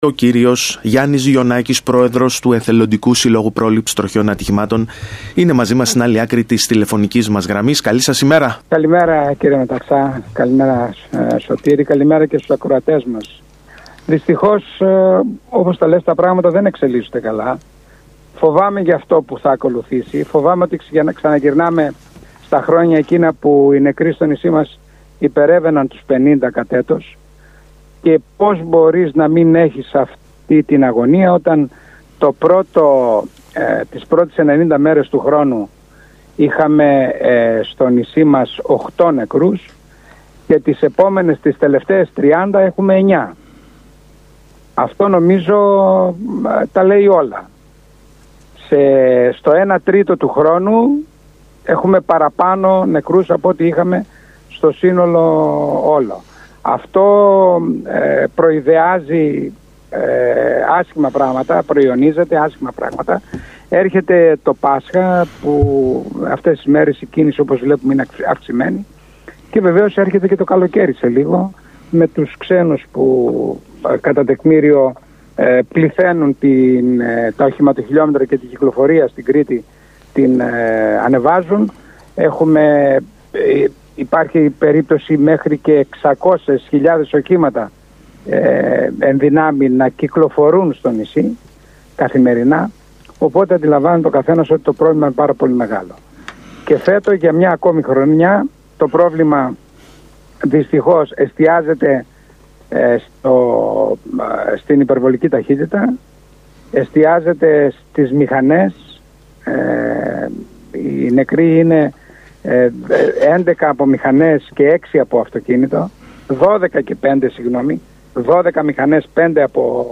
μιλώντας στον ΣΚΑΙ Κρήτης 92.1